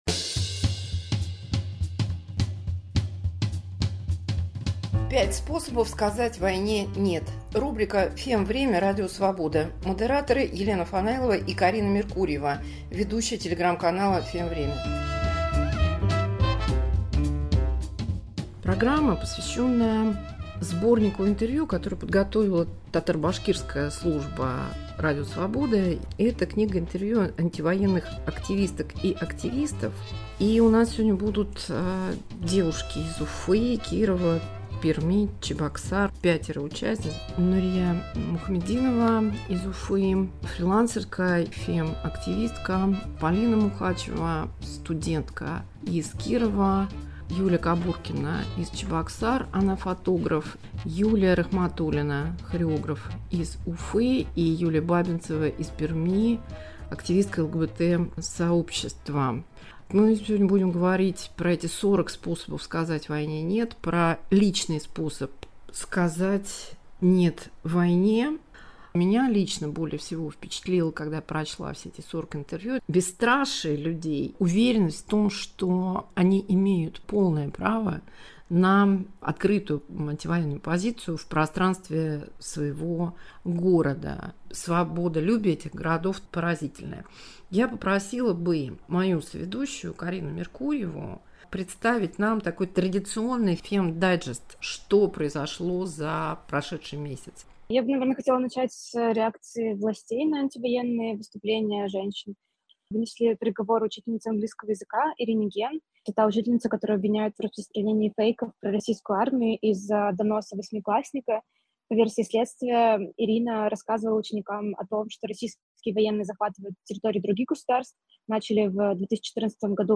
Активистки, художницы, правозащитницы.